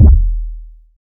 Kicks
KICK.67.NEPT.wav